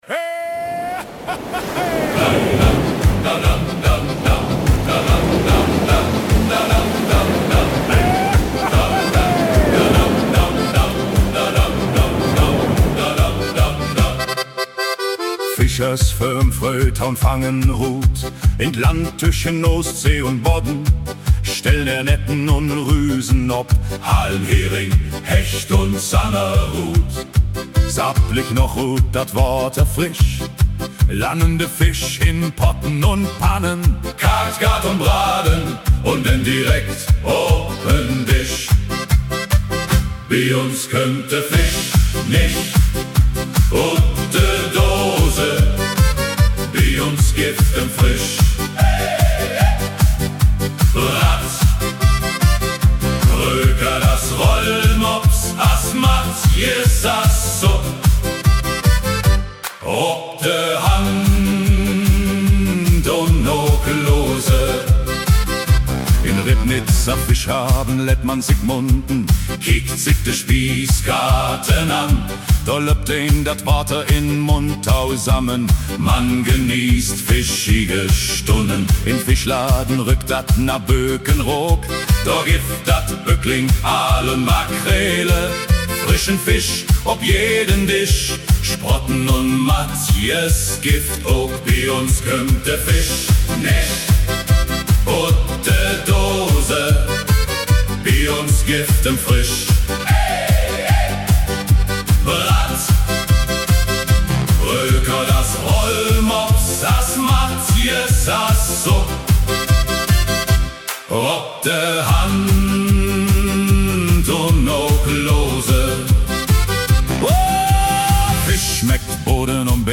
2_ribfish_plattdeutsch_shanty.mp3